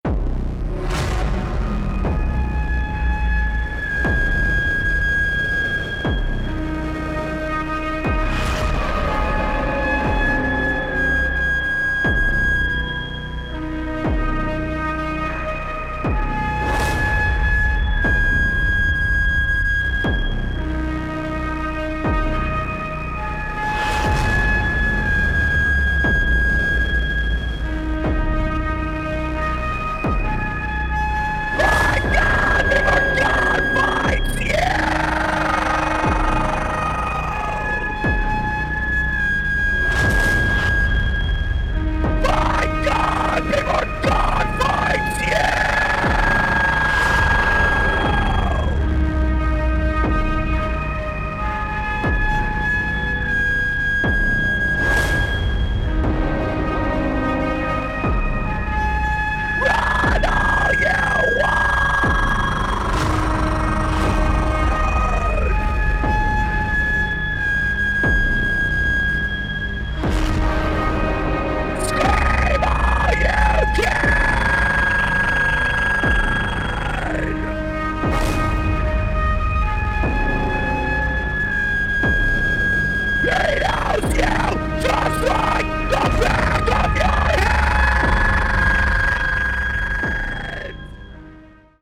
Uncompromising Power-Electronics from Oakland / USA.
• Genre: Power Electronics